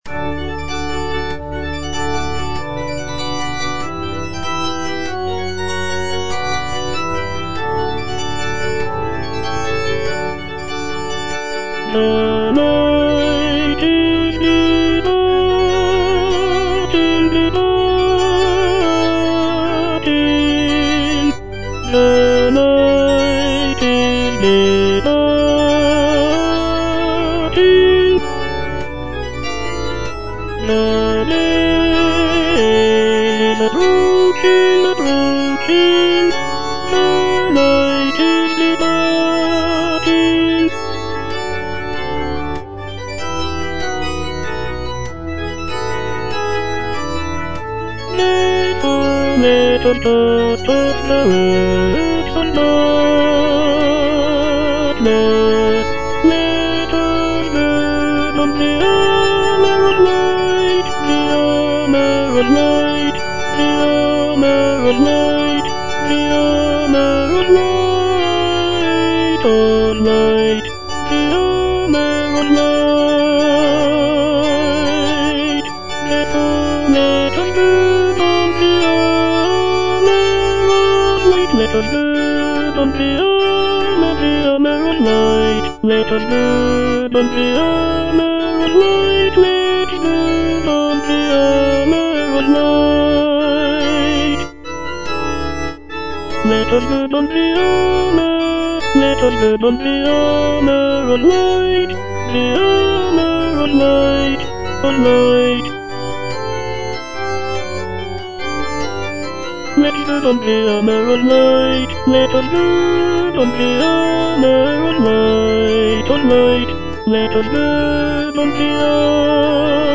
F. MENDELSSOHN - HYMN OF PRAISE (ENGLISH VERSION OF "LOBGESANG") The night is departing (tenor II) (Voice with metronome) Ads stop: